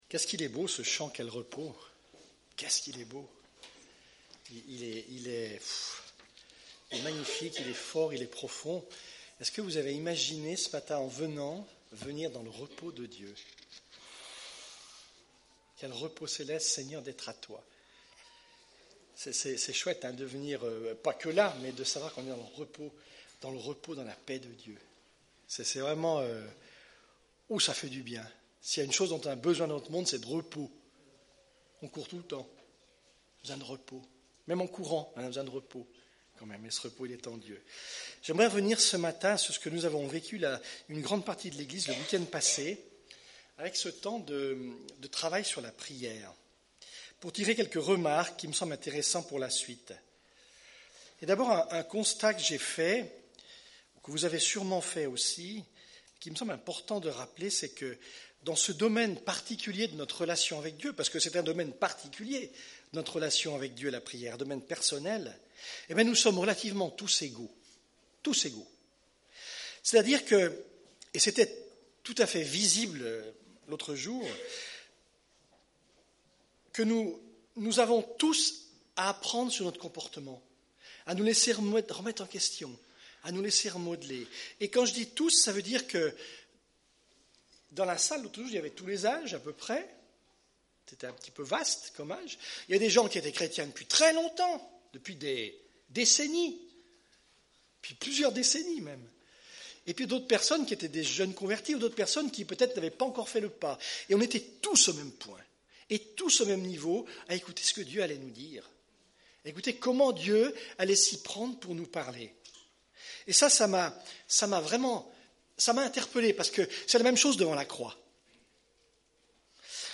Preacher
Culte du 27 janvier